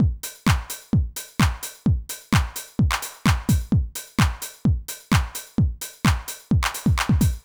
INT Beat - Mix 5.wav